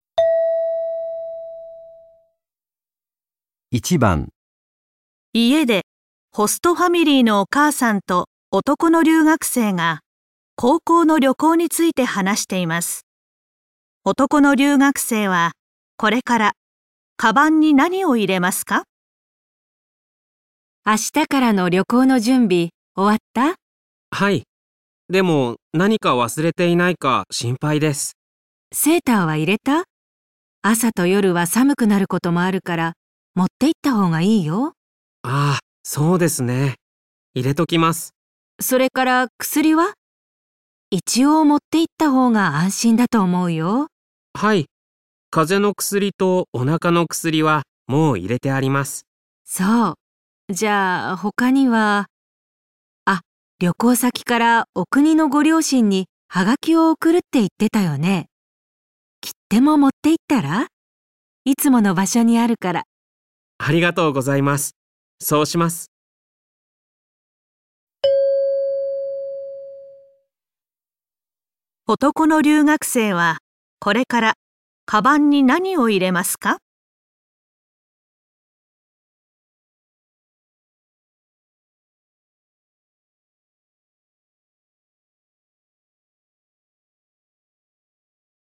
家中，寄宿家庭的母亲与男留学生正围绕高中的旅行谈话。